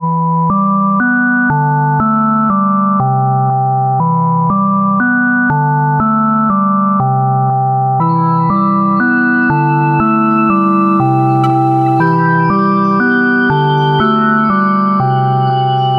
描述：低潮音乐
Tag: 60 bpm Ambient Loops Synth Loops 1.35 MB wav Key : E